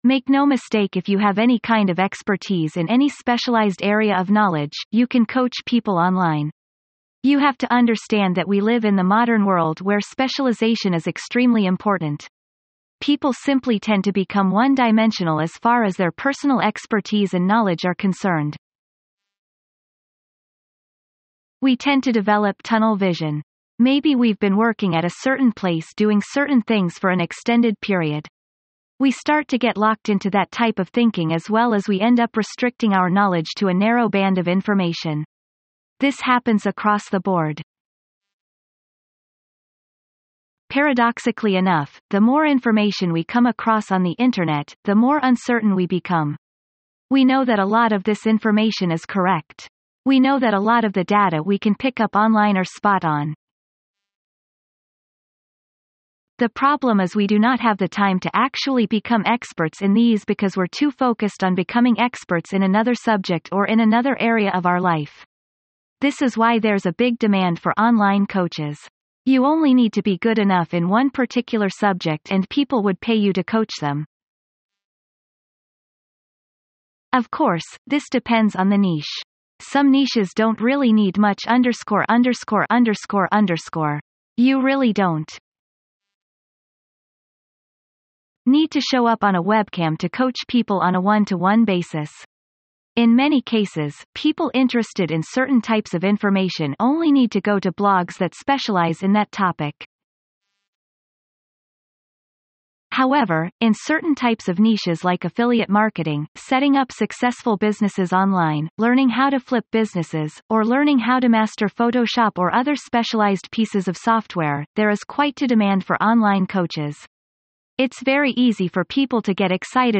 This resource walks you through what it really takes to launch and grow an online coaching business. From finding your niche to building trust and attracting clients, you’ll get clarity on how to start strong. Includes an AI-narrated audio session and a matching eBook with steps and insights to help you move forward.